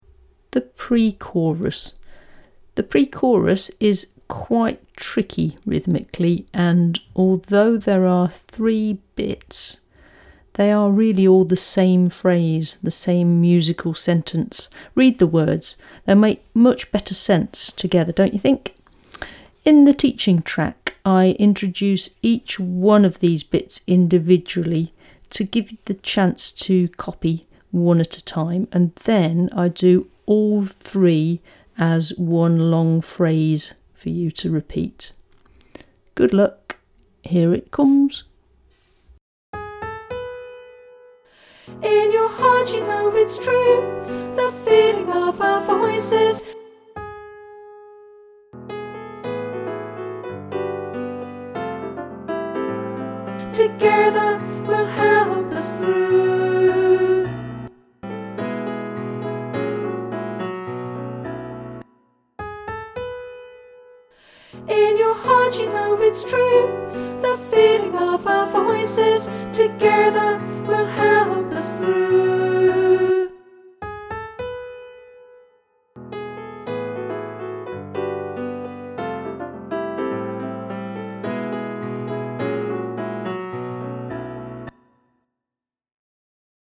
The other tracks will help you learn each section before you sing it all through with the full-length track.
pre-chorus